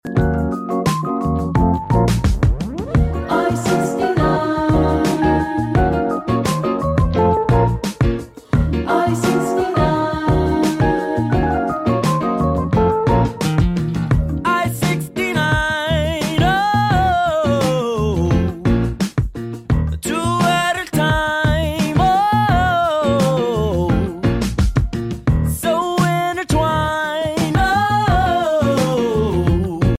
Yacht Rock Songs
Smooth vocals, groovy vibes, and a hint of modern soul